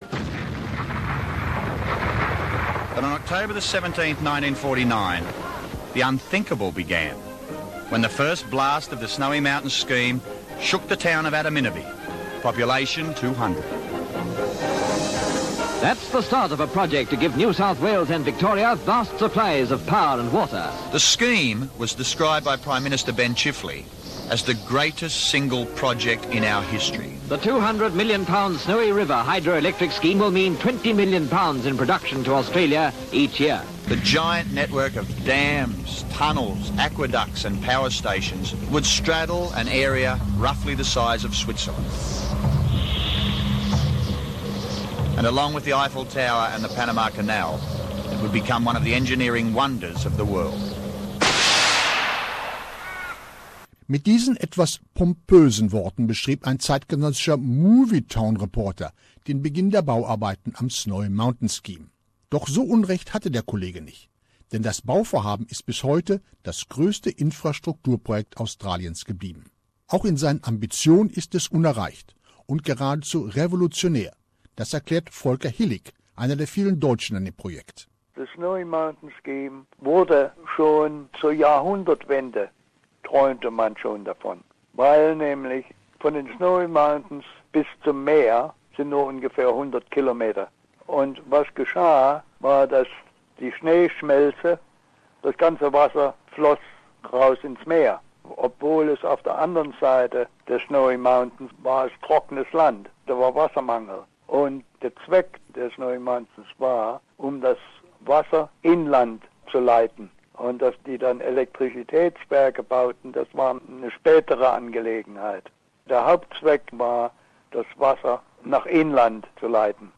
2015 wurde die Reportage beim NSW Premier Multicultural Media Award ausgezeichnet